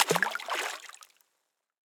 splash_small.ogg